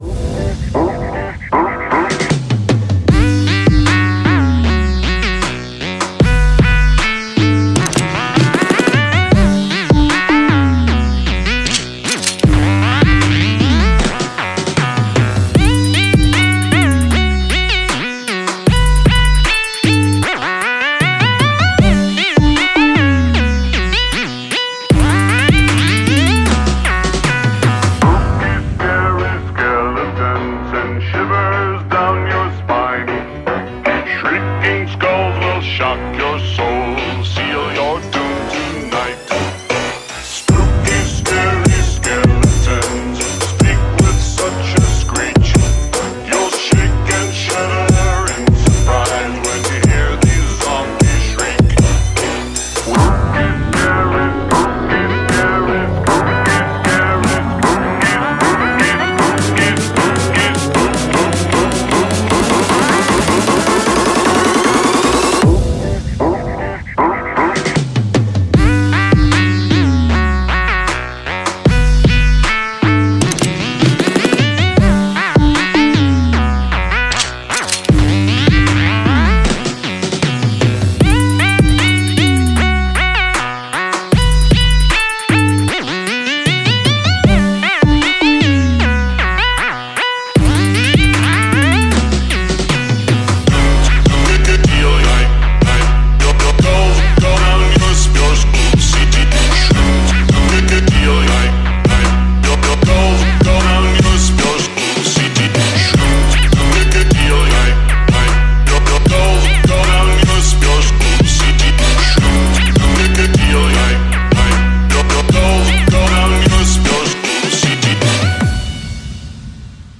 • Качество: 128, Stereo
мужской голос
страшные